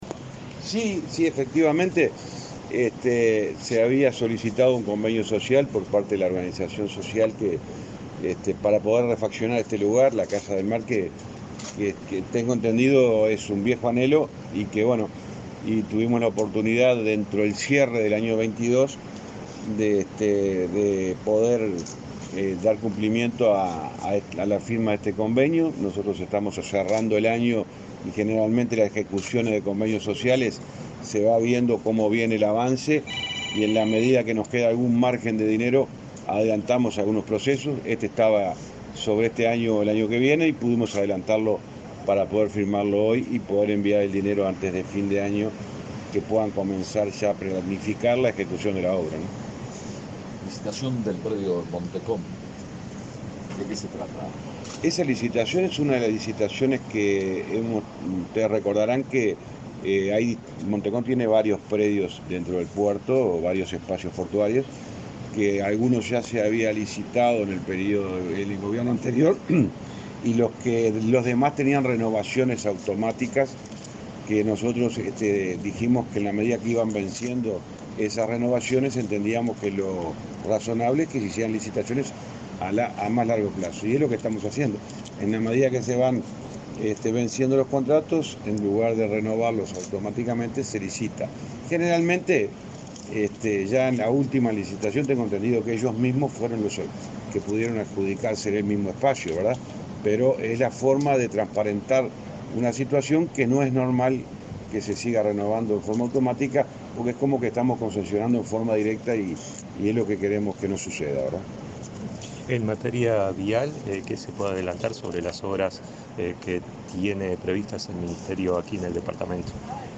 Declaraciones a la prensa del ministro de Transporte, José Luis Falero
El ministro de Transporte y Obras Públicas, José Luis Falero, dialogó con la prensa antes de firmar un convenio social con Proyecto SOS en el